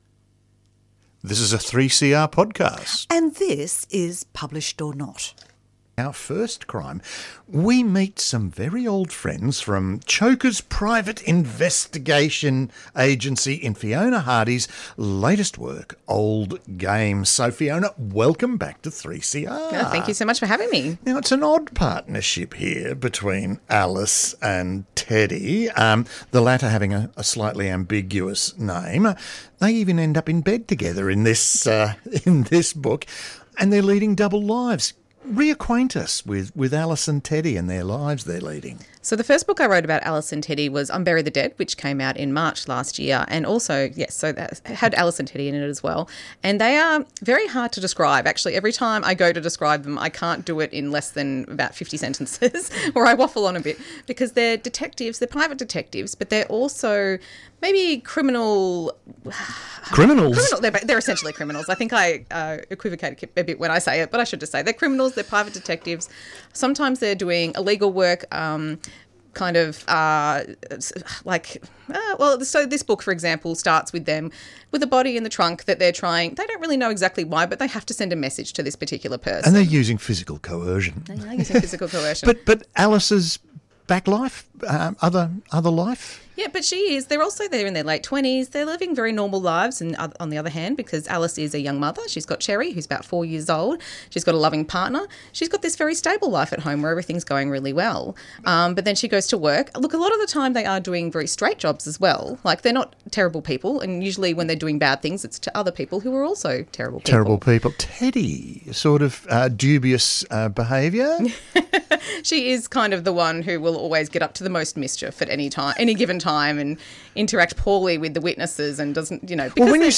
Tweet Published...Or Not Thursday 11:30am to 12:00pm Australian and international authors talk about their books and how they got published or how they self-published.